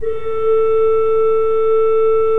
Index of /90_sSampleCDs/Propeller Island - Cathedral Organ/Partition K/KOPPELFLUT R